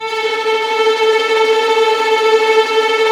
Index of /90_sSampleCDs/Roland - String Master Series/STR_Vlns Tremelo/STR_Vls Trem wh%